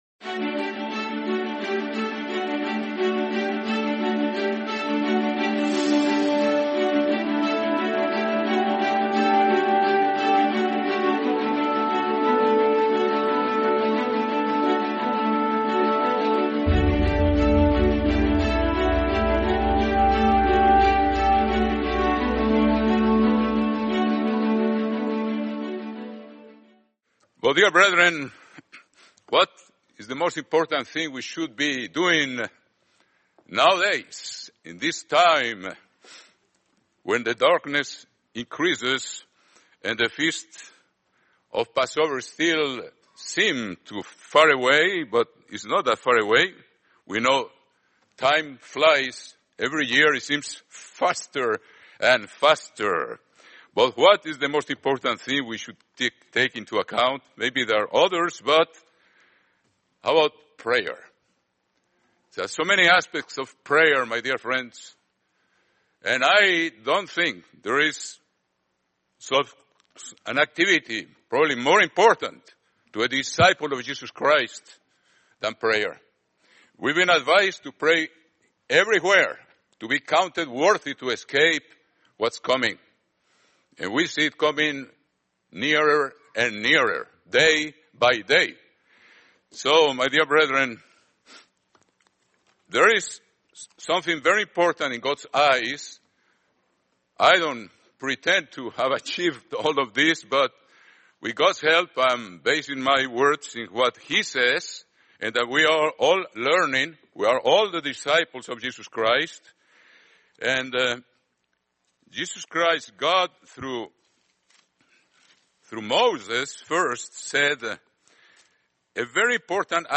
Living Church of God - Audio Sermon Library